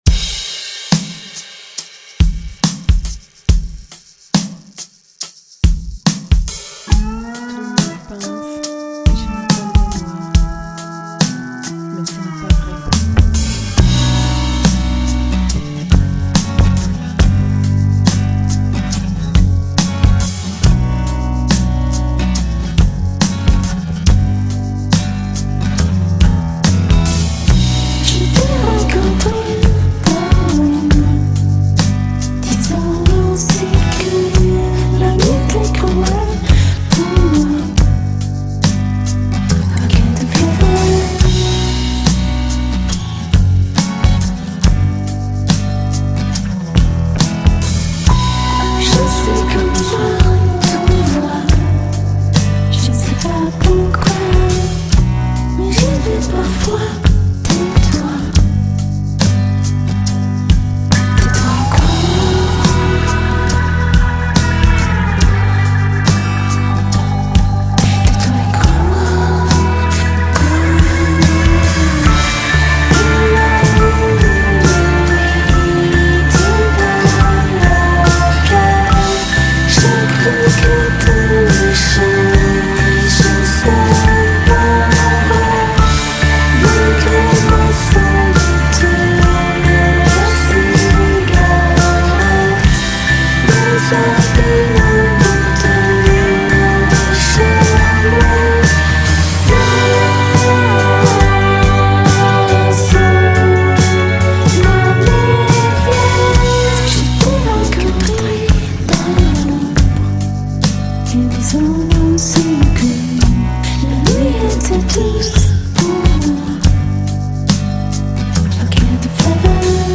canzone scritta
canta (per la prima volta e per gioco) e io  suono con un piccolo
batteria
piano elettrico